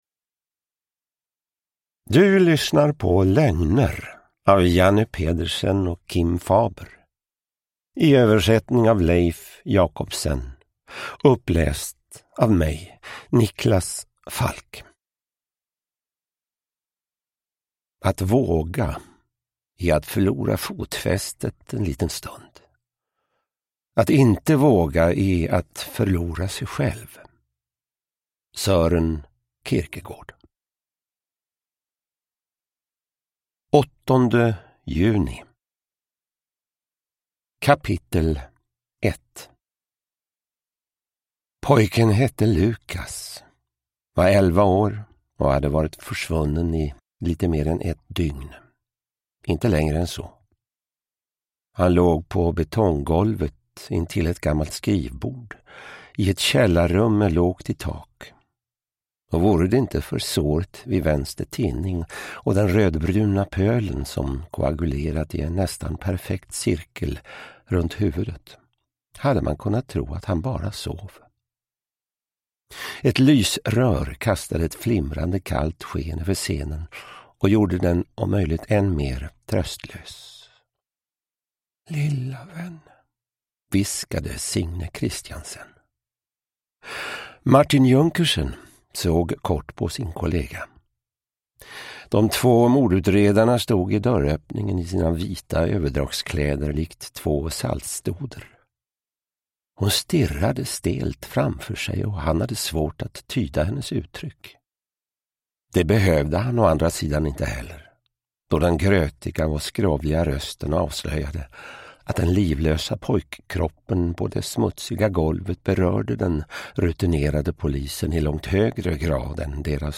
Uppläsare: Niklas Falk
Ljudbok